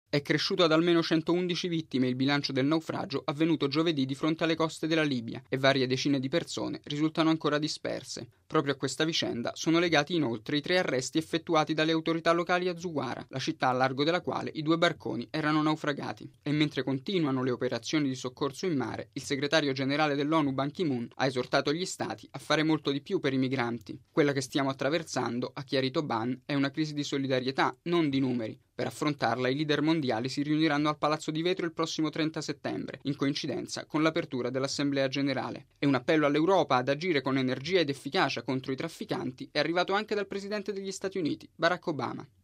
Si aggrava il bilancio dell’ultimo naufragio di migranti al largo della Libia, mentre dalla comunità internazionale arrivano appelli a coordinare gli sforzi per evitare nuove tragedie e l’Onu annuncia un vertice straordinario a settembre. Il servizio